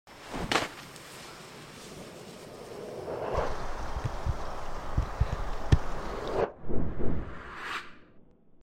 White T Shirt Transition 🔥 Join sound effects free download